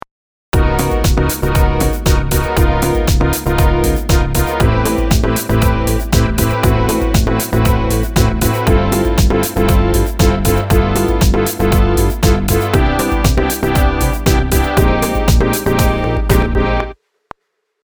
プリセットから“055 SynthTwins i”というのを選択してみました。
ちょっと厚みが出てきた感じしますね。このシンセブラスはミキサーで左に振られているので、エフェクトのサウンドも同じようにパンされています。